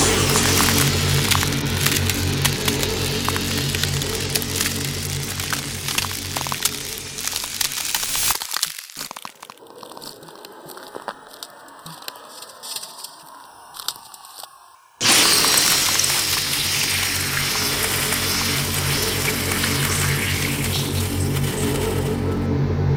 The sound itself was built with the hum fading out into a “frozen tundra” sort of sound with slow crackling ice (crushing a plastic bottle!), then when the “thaw” happens, it fades back into full hum again.